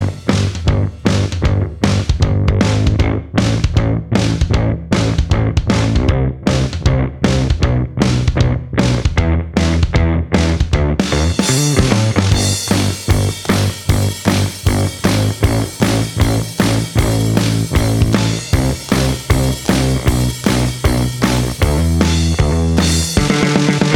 Minus Guitars Indie / Alternative 3:34 Buy £1.50